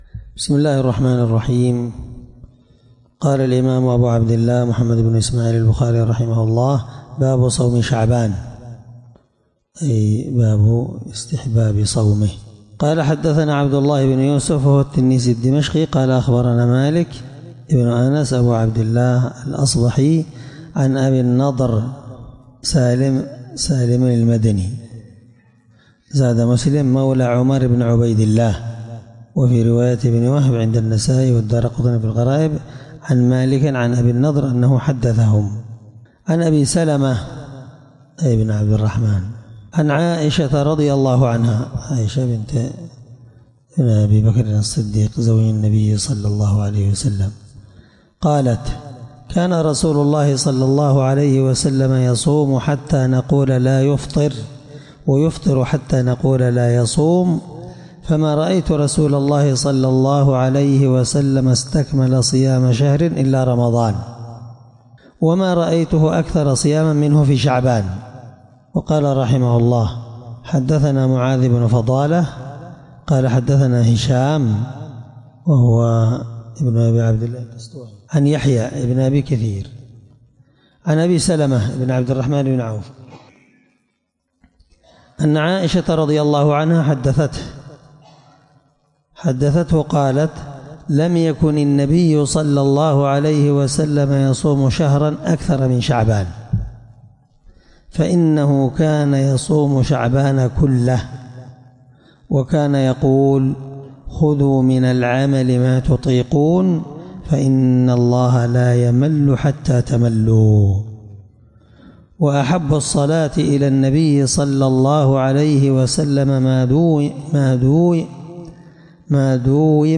الدرس48 من شرح كتاب الصوم رقم(1969-1970)من صحيح البخاري